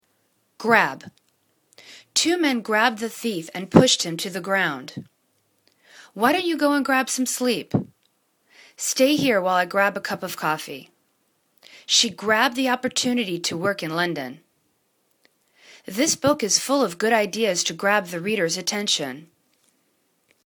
grab    /grab/    v